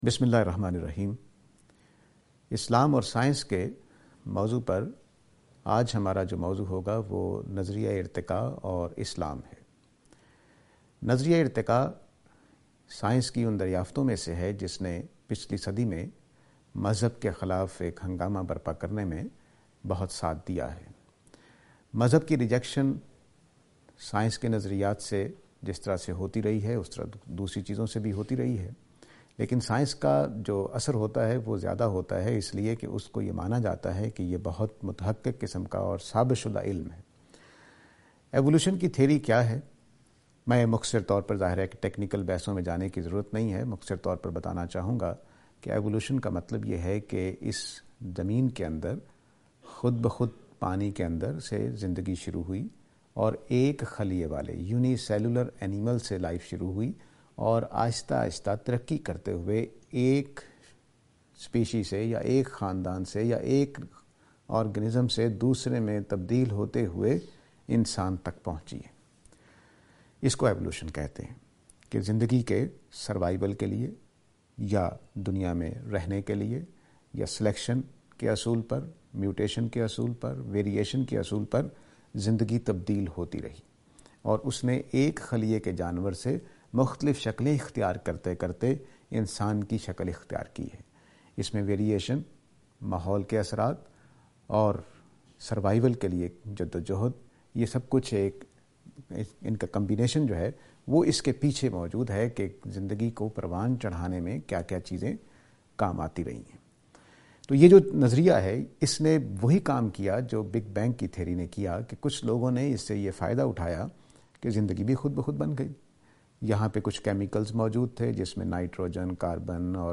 This lecture is and attempt to answer the question "Creation Life: Theory of Life".